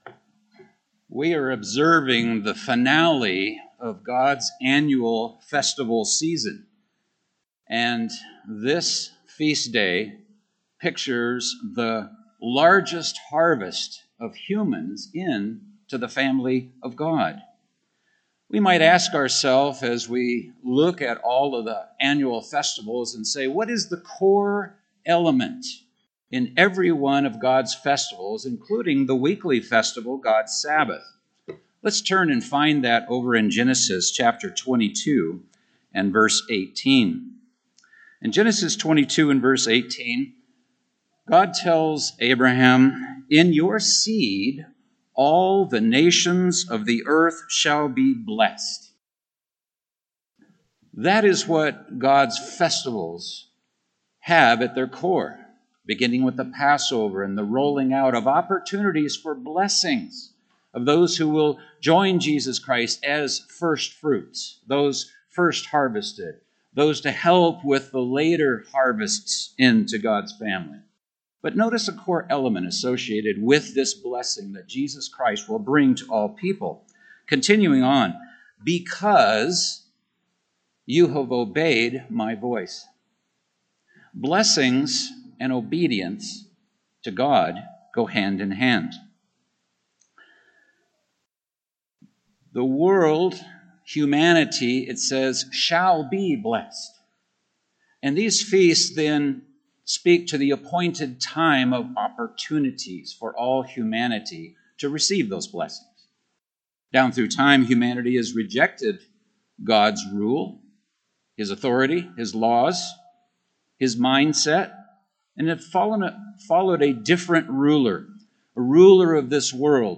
A split sermon given on the Eighth Day at Glacier Country, Montana, 2020.
This sermon was given at the Glacier Country, Montana 2020 Feast site.